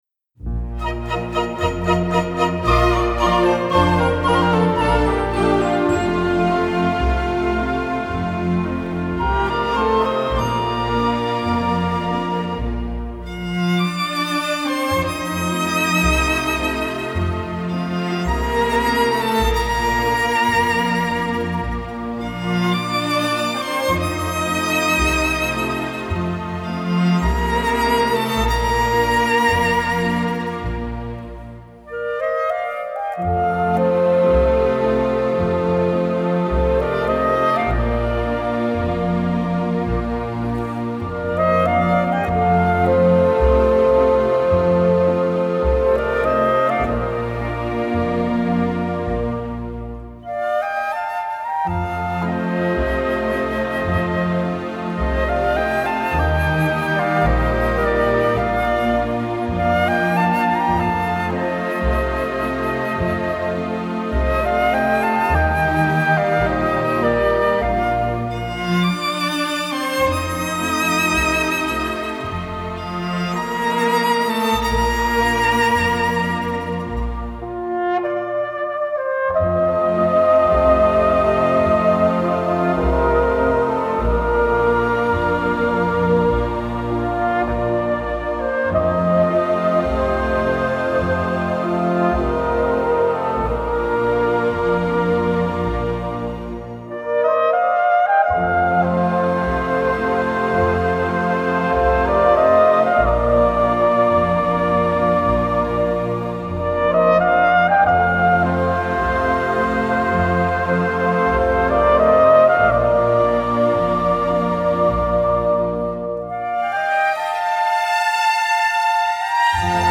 Genre: Pop, Instrumental, Easy Listening